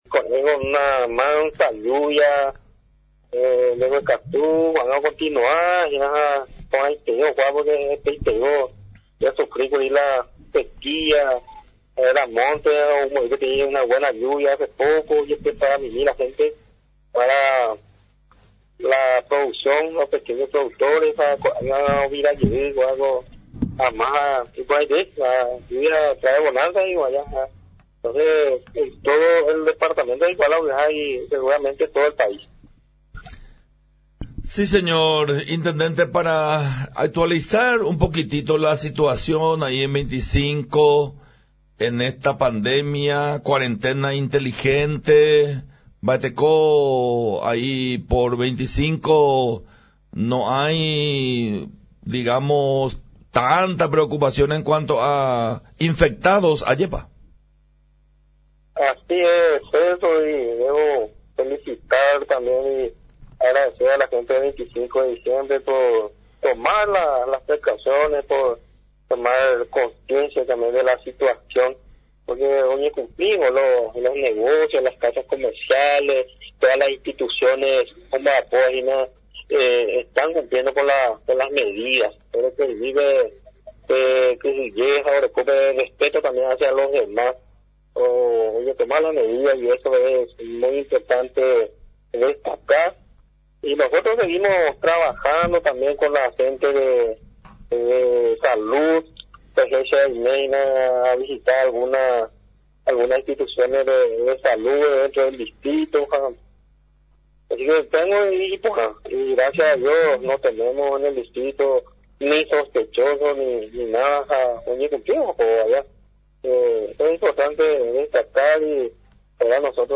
El intendente del distrito de 25 de Diciembre, ingeniero Sindulfo Benítez, informó que contarán con el primer cajero automático, luego de las gestiones realizadas por el Banco Nacional de Fomento.